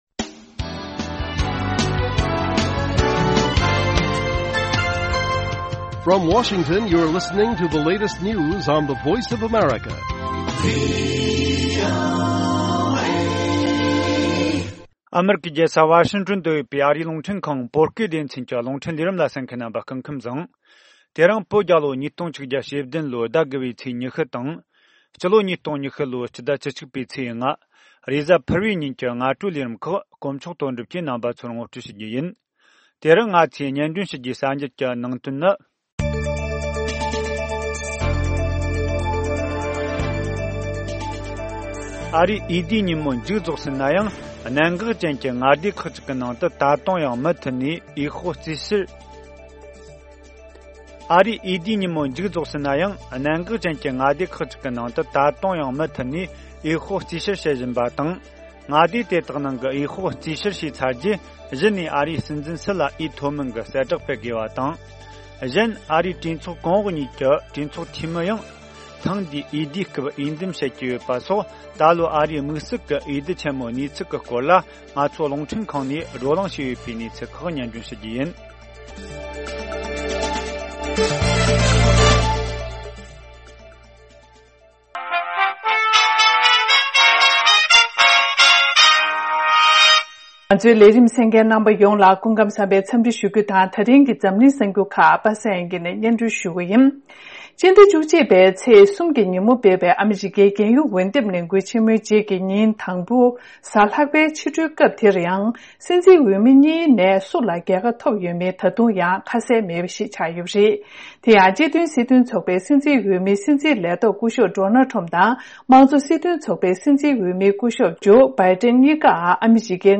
ཨ་རིའི་འོས་བསྡུའི་ཉིན་མོ་མཇུག་རྫོགས་ཟིན་ནའང་གནད་འགག་ཅན་གྱི་མངའ་སྡེ་ཁག་ཅིག་གི་ནང་དུ་ད་དུང་ཡང་མུ་མཐུད་ནས་འོས་ཤོག་རྩིས་བཤེར་བྱེད་བཞིན་པ་དང་། མངའ་སྡེ་དེ་དག་ནང་གི་འོས་ཤོག་རྩིས་བཤེར་བྱས་ཚར་རྗེས་གཞི་ནས་ཨ་རིའི་སྲིད་འཛིན་སུ་ལ་འོས་ཐོབ་མིན་གྱི་གསལ་བསྒྲགས་སྤེལ་དགོས་པ་དང་། གཞན་ཨ་རིའི་གྲོས་ཚོགས་གོང་འོག་གཉིས་ཀྱི་གྲོས་ཚོགས་འཐུས་མི་ཡང་འོས་ཐེངས་འདིའི་འོས་བསྡུའི་སྐབས་འོས་འདེམས་་བྱས་ཡོད་པ་སོགས་ད་ལོའི་ཨ་རིའི་དམིགས་བསལ་གྱི་འོས་བསྡུ་ཆེན་མོའི་གནས་ཚུལ་གྱི་སྐོར་ལ་ང་ཚོའི་རླུང་འཕྲིན་ཁང་ནས་བགྲོ་གླེང་བྱས་ཡོད་པའི་གནས་ཚུལ་ཡོད།